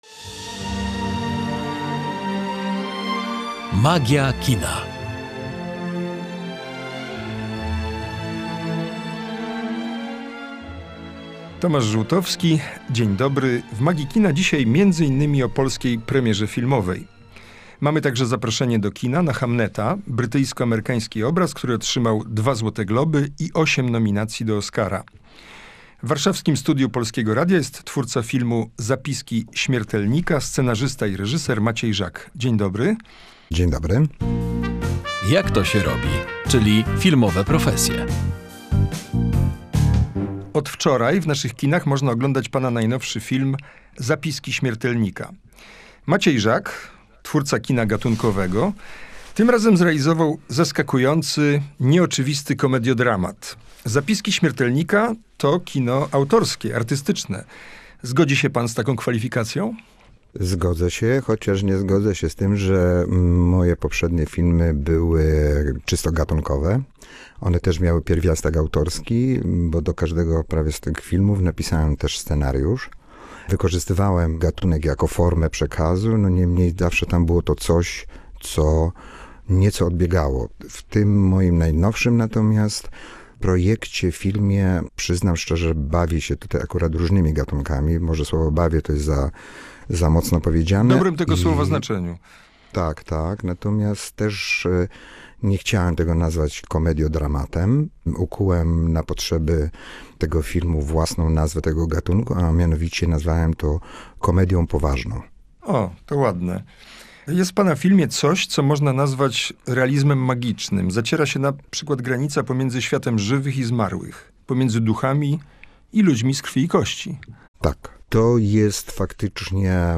Kino gatunkowe i artystyczne. Dyskusja o najnowszym filmie